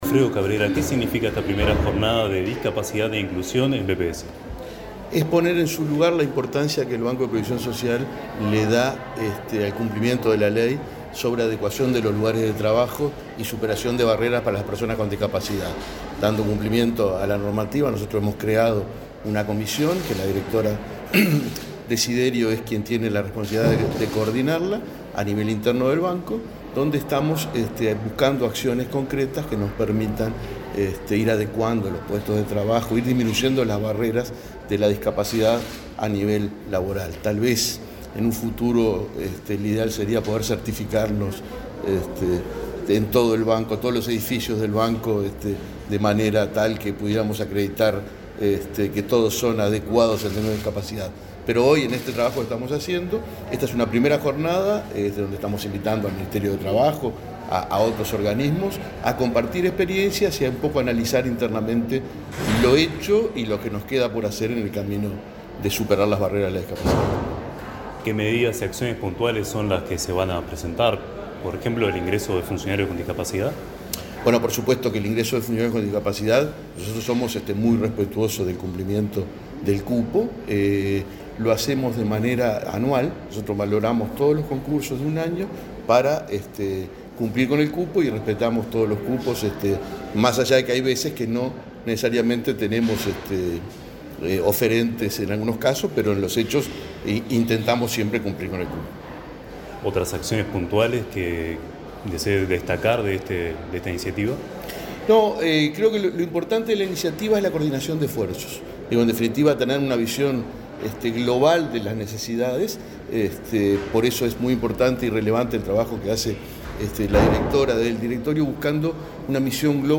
Entrevista al presidente del BPS, Alfredo Cabrera
El presidente del Banco de Previsión Social (BPS), Alfredo Cabrera, dialogó con Comunicación Presidencial, acerca de la primera jornada de